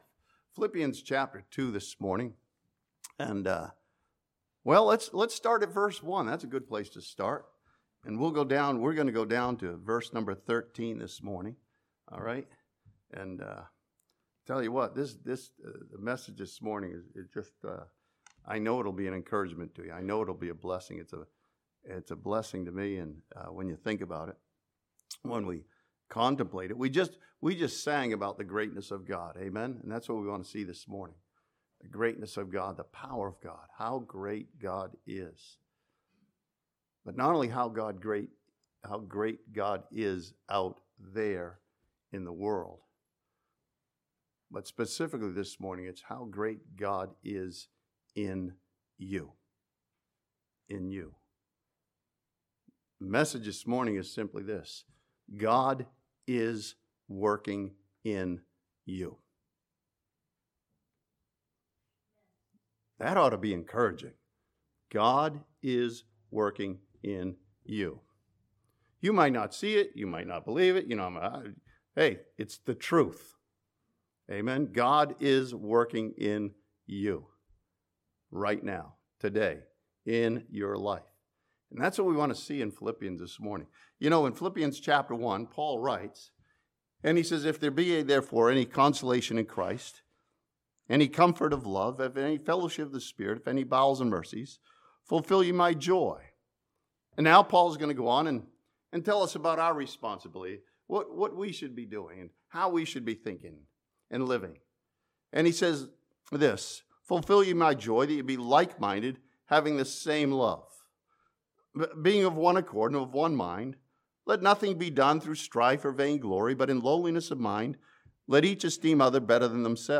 This sermon from Philippians chapter 2 reminds believers that God is working in you to accomplish His will.